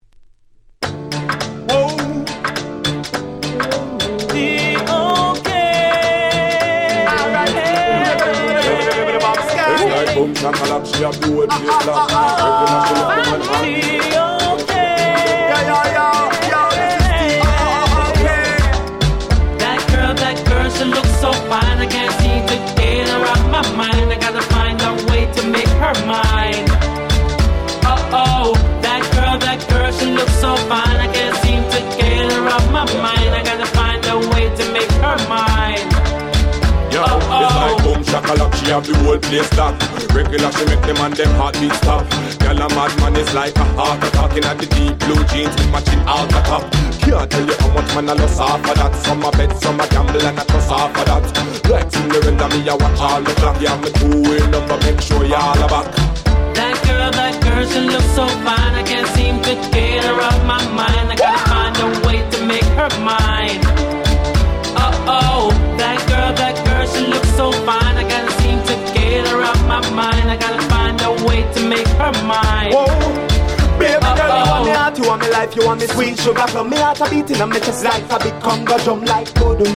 02' Super Hit Dancehall Riddim !!